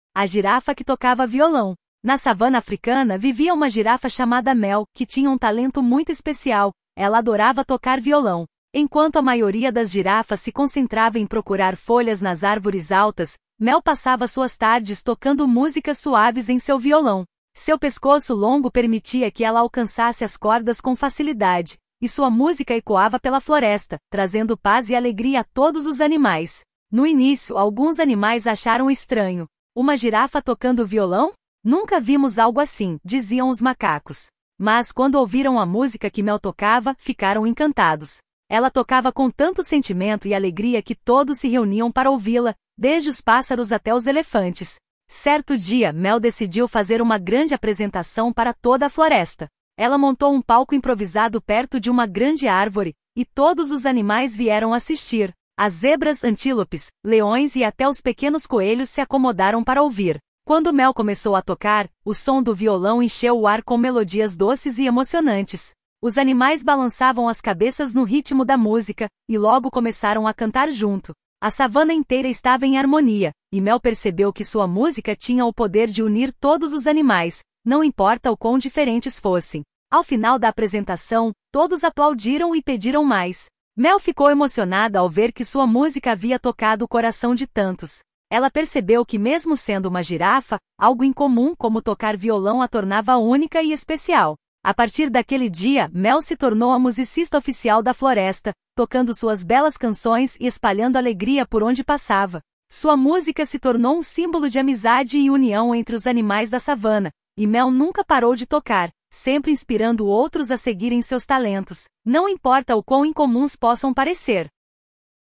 História infantil de girafa